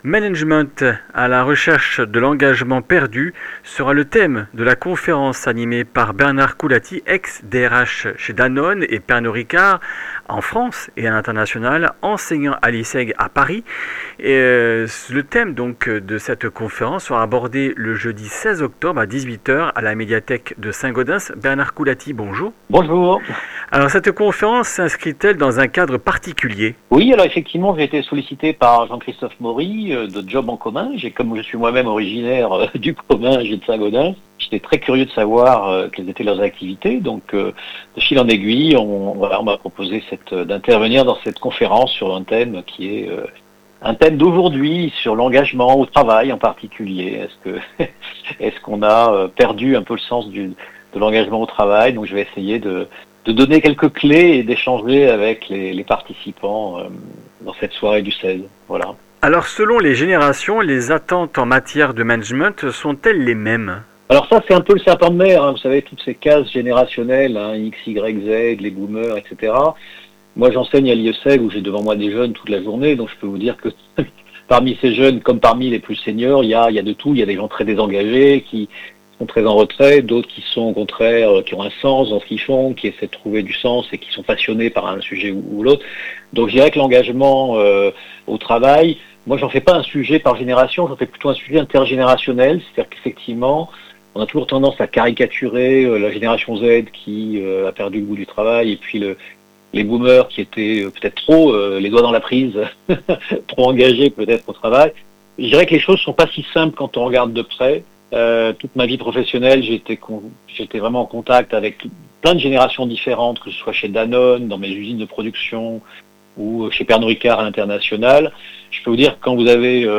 Comminges Interviews du 13 oct.